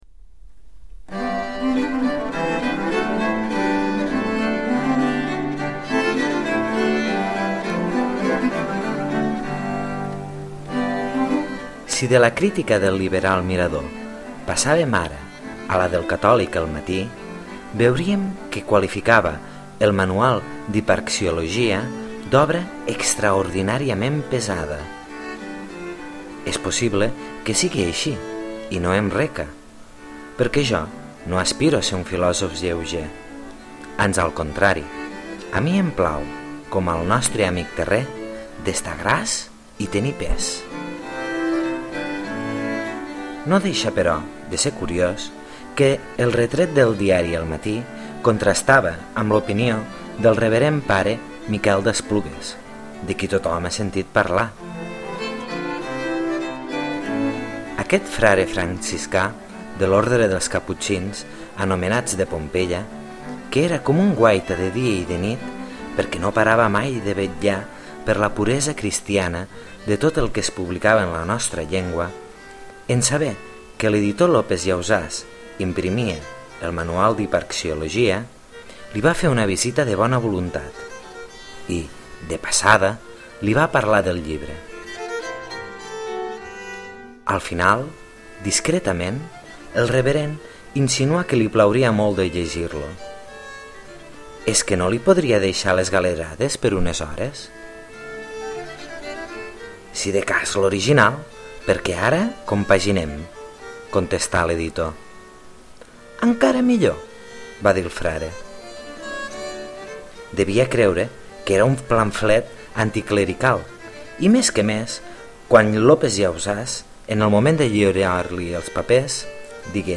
La música és interpretada per New York Consort of Viols, i és una peça de Anthony Holborne, un compositor de la cort de la Pèrfida Albió en temps de la reina Elisabet.